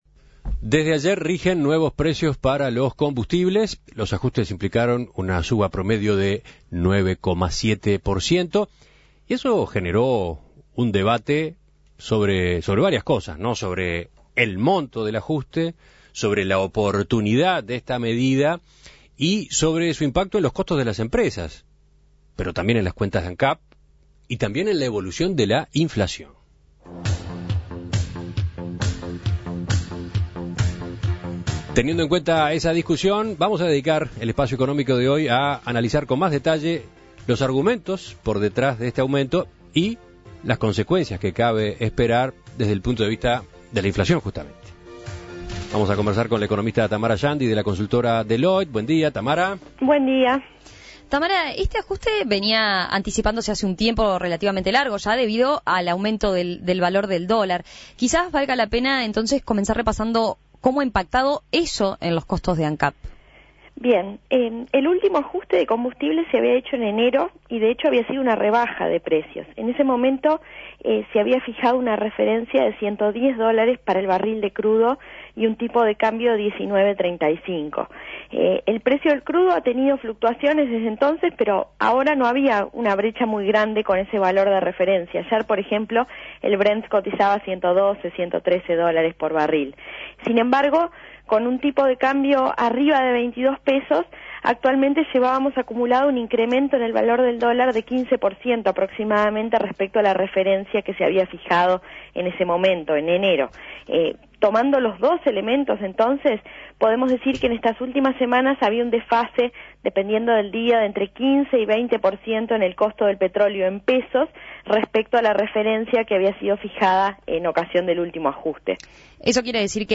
Entrevistas El aumento de los combustibles